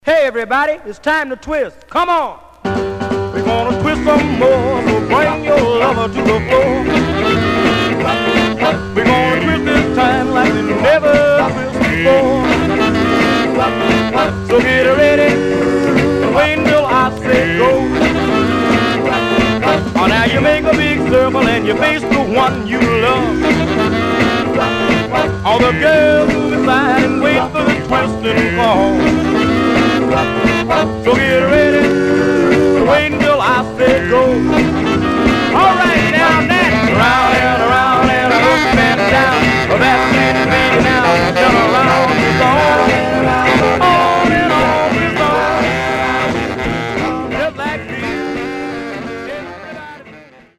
Stereo/mono Mono
45s, Male Black Groups